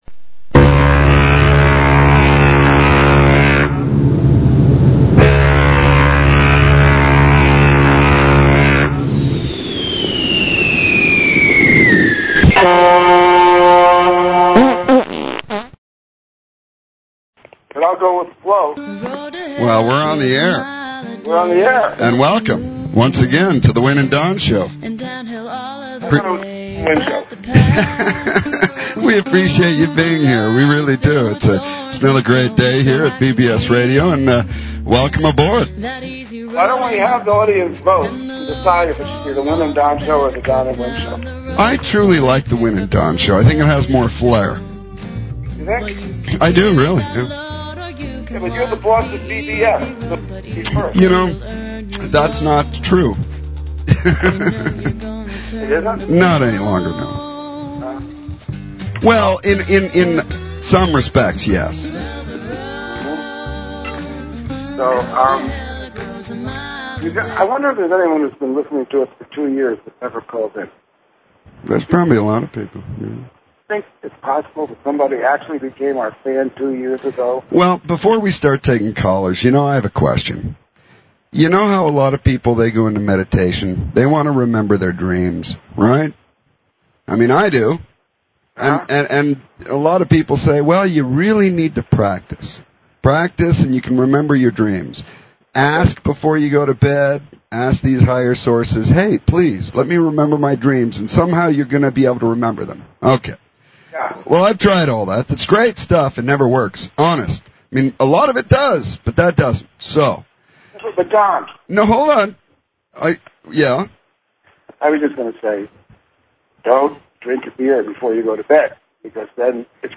Talk Show Episode, Audio Podcast
Drive time radio with a metaphysical slant.